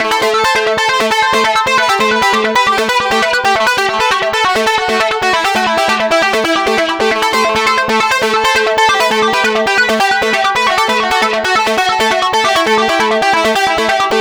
Session 04 - Trance Lead 02.wav